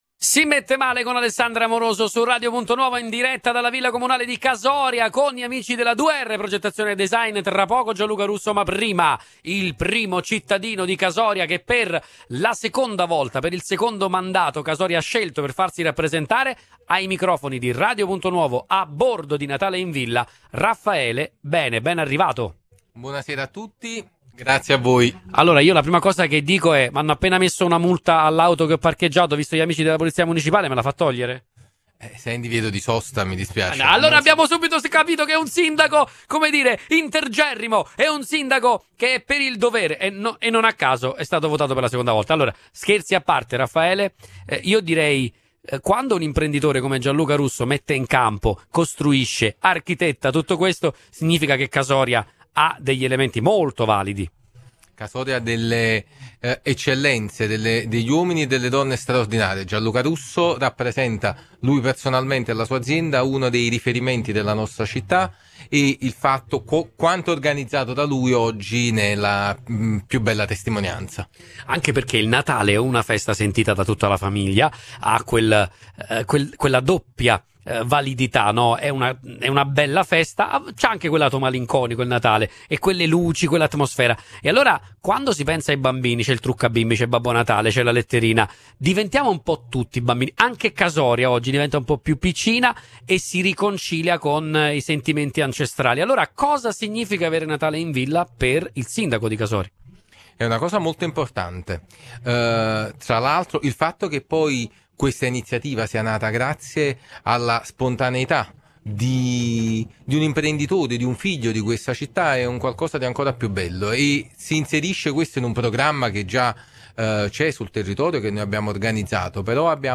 A rendere ancora più coinvolgente l’esperienza, oggi abbiamo trasmesso in diretta dall’evento, raccontando emozioni, sorrisi e i tanti desideri natalizi dei più piccoli.